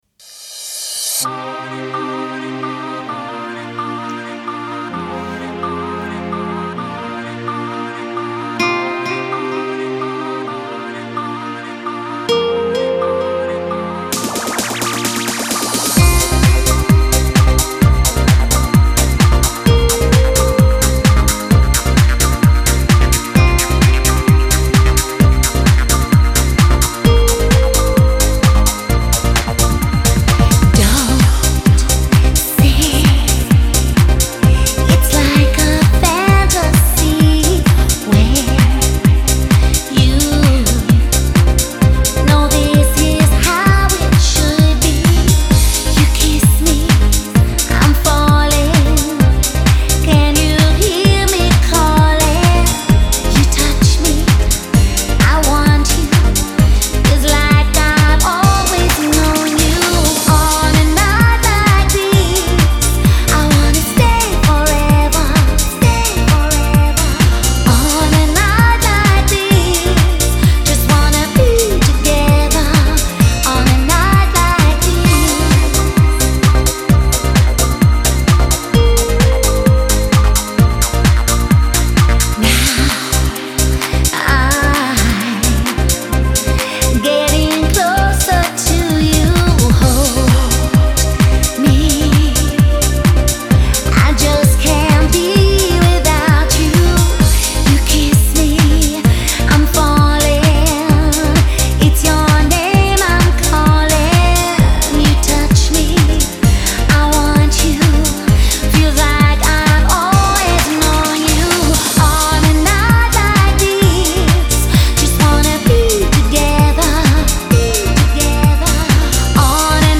Amazing sound and lookalike to the girl down under.